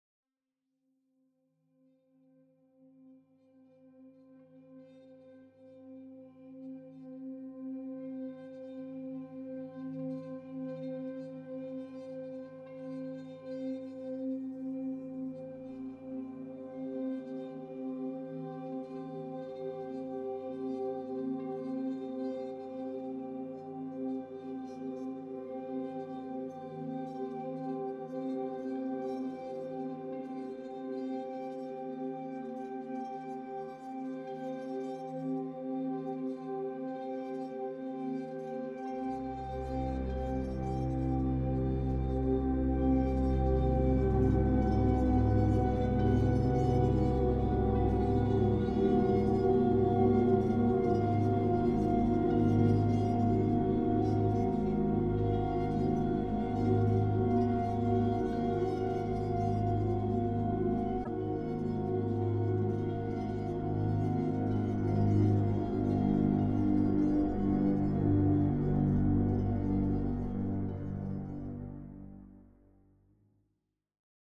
The sustained tones, however, kept the film unsettled.